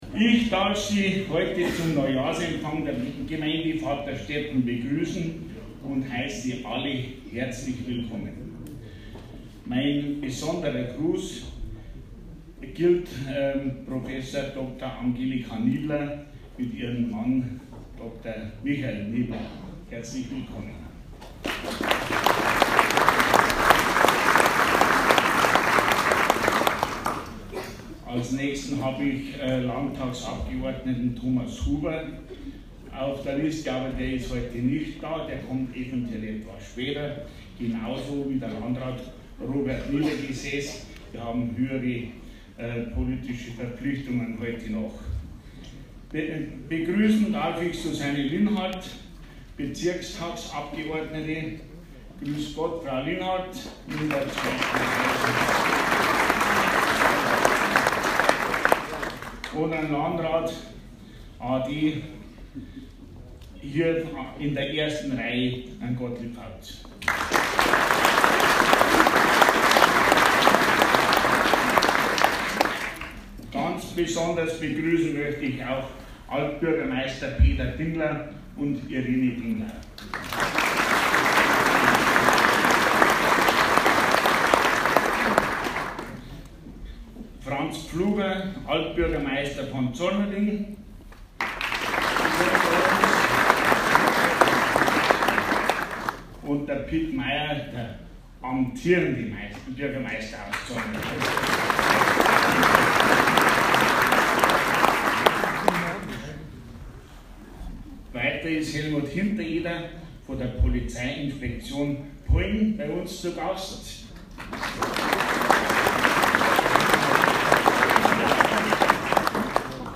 Zufriedener Bürgermeister Es folgte die Rede von Georg Reitsberger. Er sprach das Problem des Wohnraummangels und der Nachverdichtung an. Gleichzeitig mahnte er, dass auch der Gartenstadtcharakter der Gemeinde erhalten bleiben solle.